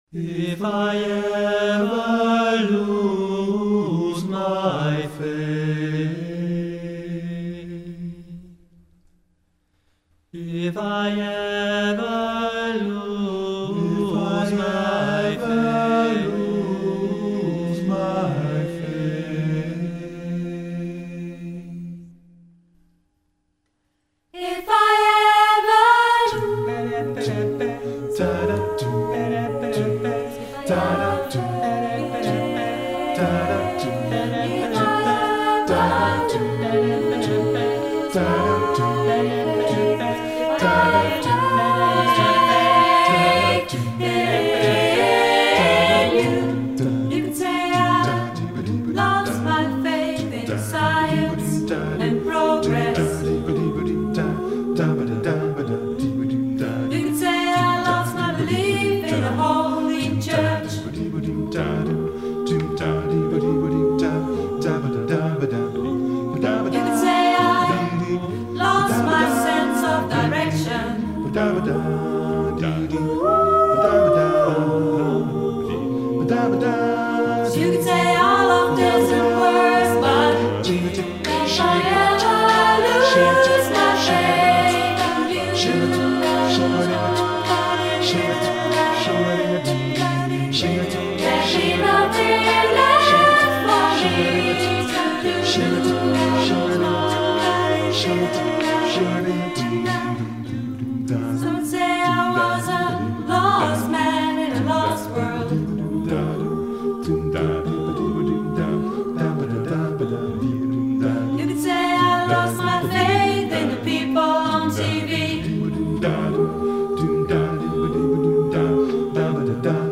Arrangementen voor koor.